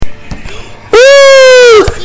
Just another scream
gahahahah.mp3